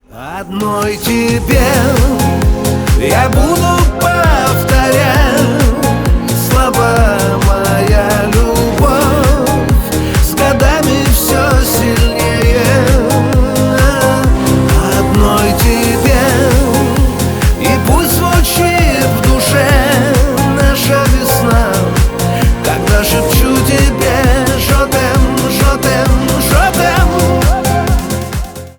Нарезка на Звонок
Шансон рингтоны